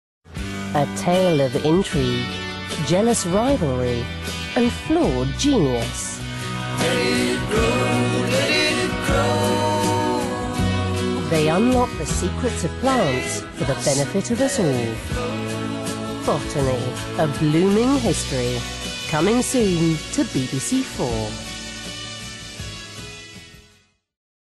Straight
Promo, Smooth, Sexy, Warm